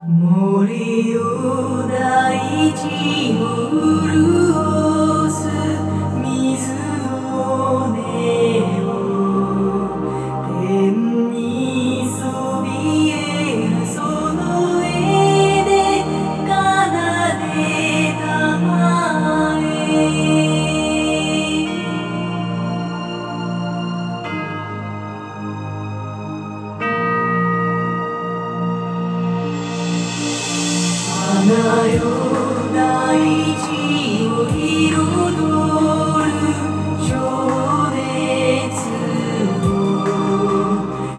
では最後にいつもと同じようにスピーカーから鳴らすCDの音を、ちょうど2つのLとRのスピーカーが90°に広がる位置にH2essentialを固定した上で、90の設定でレコーディングしてみた。
Music：
もちろん、サンプリングレートの変更やビット解像度の変更で音の傾向は変わらないのだが、聴いてみてちょっと気になるのは高域がかなり強めに出ているという点。